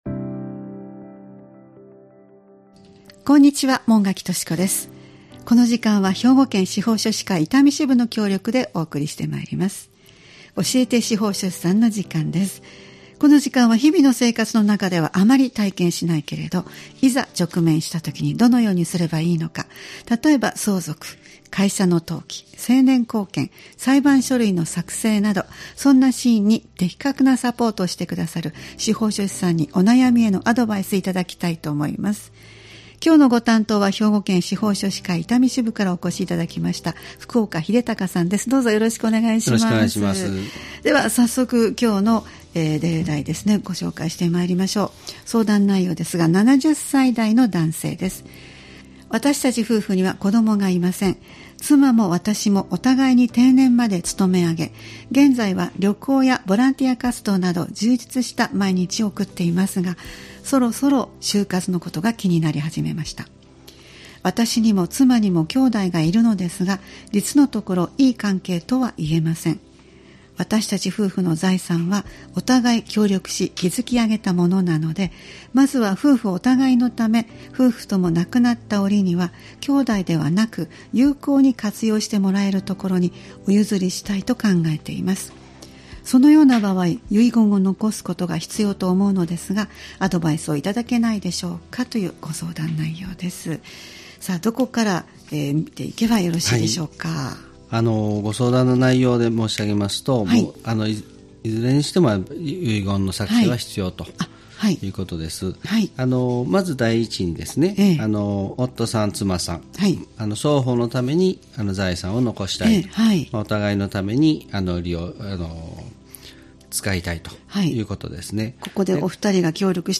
毎回スタジオに司法書士の方をお迎えして、相続・登記・成年後見・裁判書類の作成などのアドバイスを頂いています。